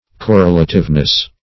Correlativeness \Cor*rel"a*tive*ness\, n.